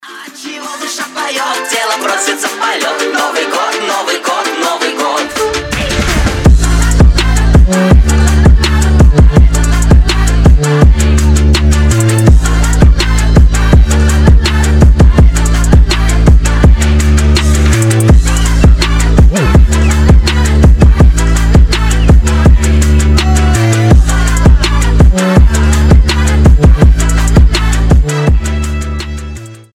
• Качество: 320, Stereo
веселые
мощные басы
качающие
ремиксы